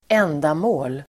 Uttal: [²'en:damå:l]